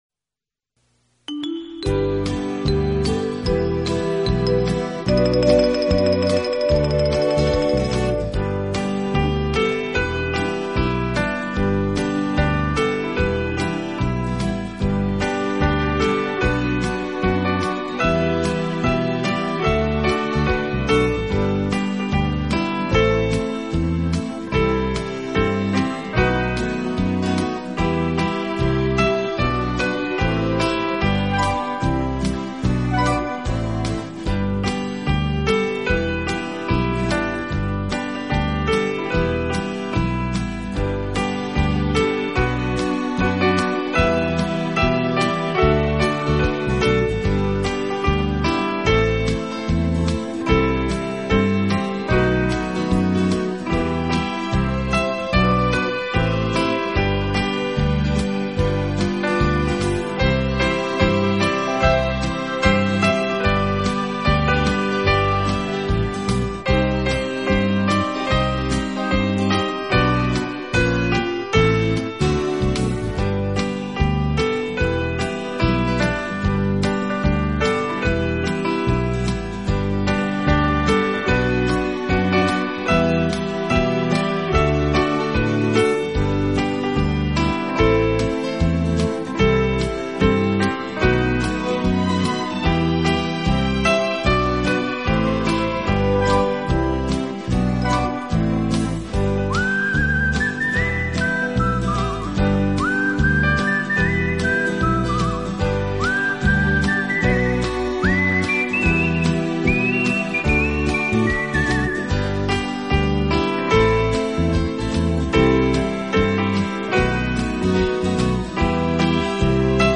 这是一套非常经典的老曲目经过改编用钢琴重新演绎的系列专辑。
而缠绵悱恻。
本套CD全部钢琴演奏，